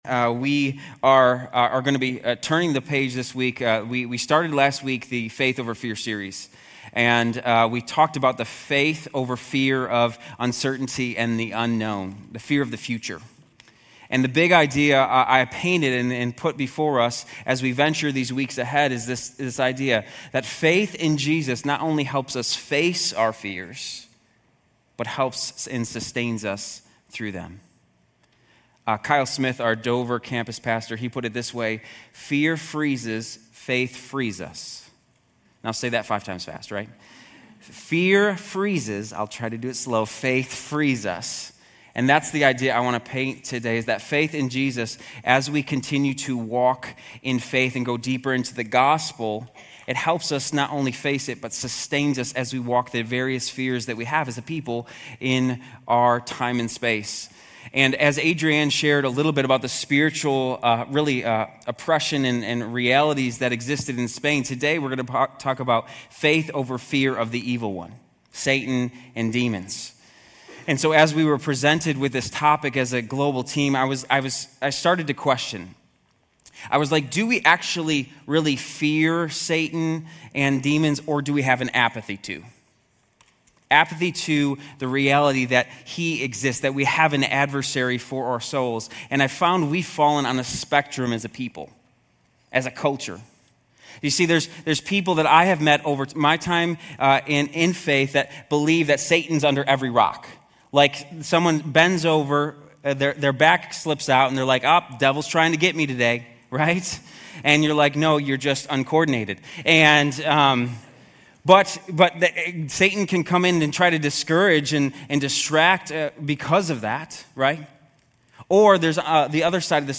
Grace Community Church University Blvd Campus Sermons 5_4 University Blvd Campus May 05 2025 | 00:29:34 1x 00:00 / 00:29:34 Subscribe Share RSS Feed Share Link Embed